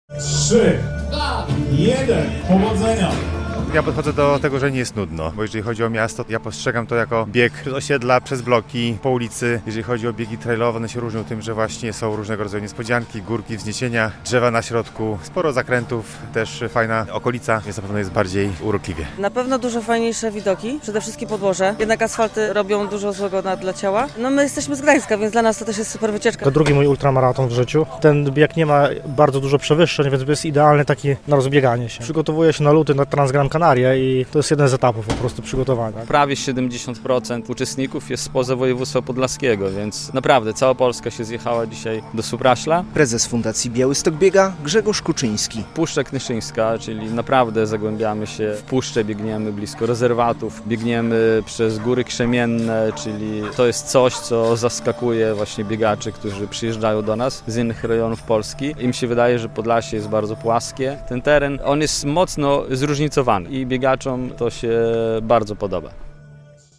Pierwsi biegacze już wystartowali - relacja